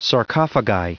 Prononciation du mot sarcophagi en anglais (fichier audio)